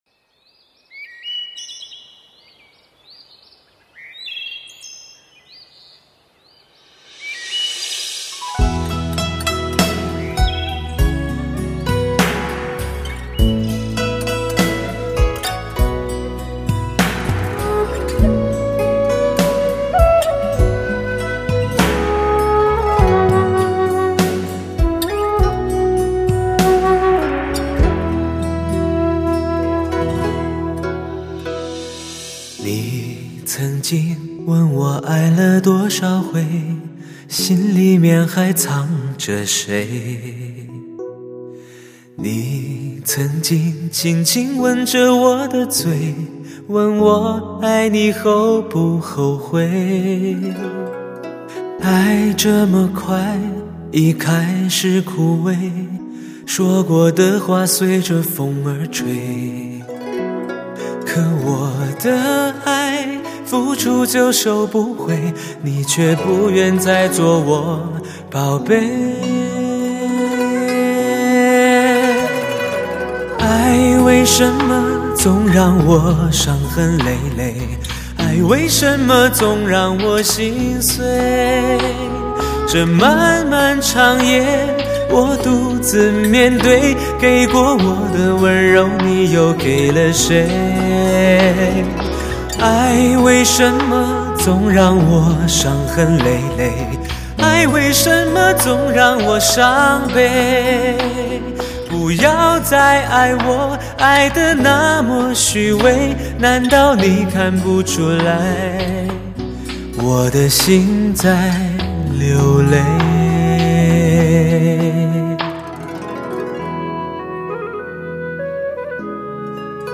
类型: 汽车音乐
感性醇厚的男人心声独白，会让落寞的心，再度温暖起来。